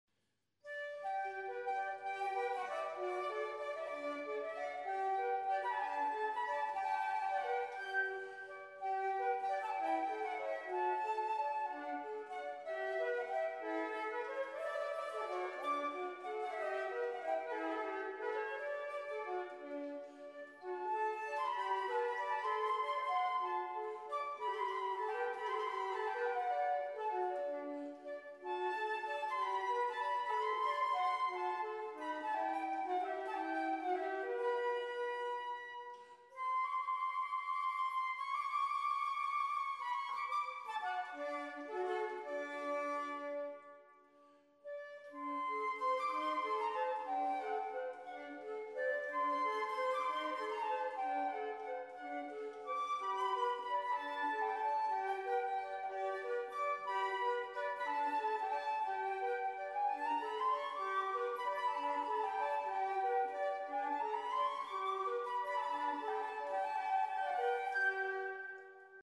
für 2 Flöten
そのため、この非常に明るくユーモアのある音楽を発見することは、非常に価値のあることです。